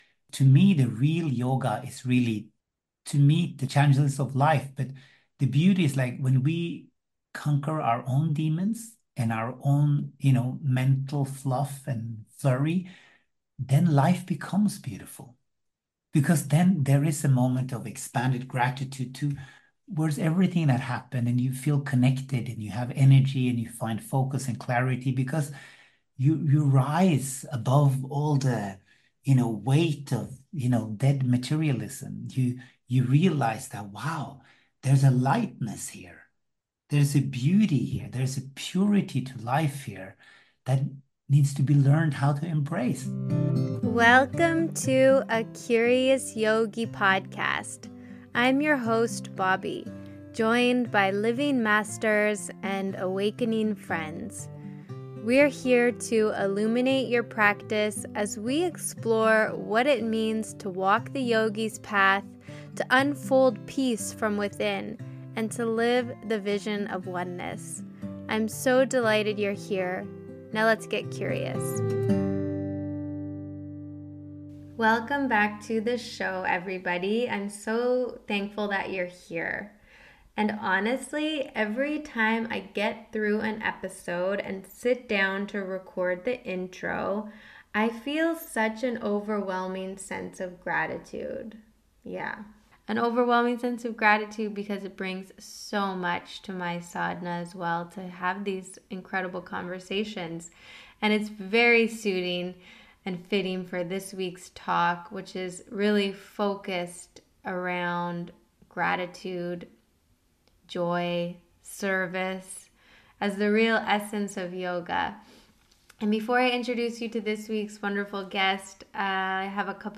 Join me weekly for joyful conversations with wise teachers, sincere yogis and other spiritual seekers. We inquire into our deepest questions and learn how to apply the ancient wisdom into our own daily practice…and life.